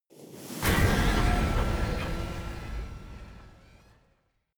Royalty free sounds: Horror